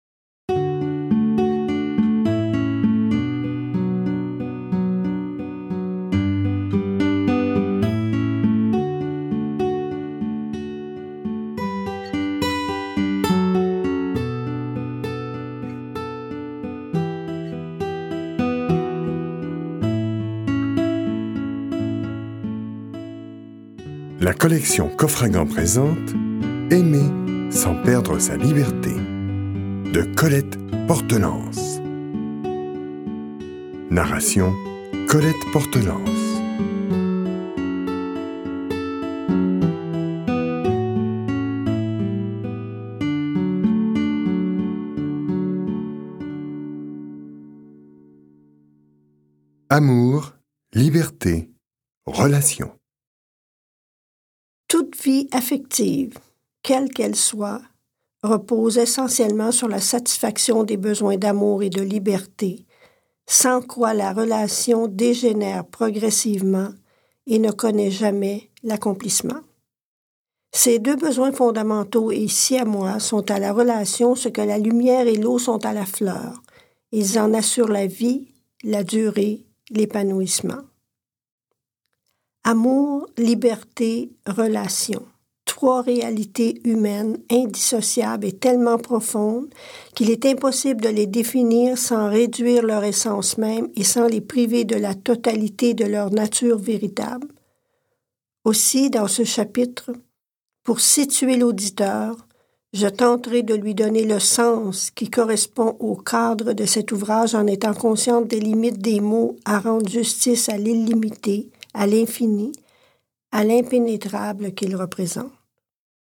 Ce livre audio a été écrit spécialement pour tous les amoureux du monde, pour les couples, pour ceux qui éprouvent un grand besoin d'amour et une soif énorme de liberté. Il s'adresse particulièrement à toutes ces personnes qui hésitent à s'engager dans une vie amoureuse de crainte de perdre leur précieuse liberté d'être ce qu'ils sont, cette liberté de vivre en accord avec leurs valeurs et leurs priorités.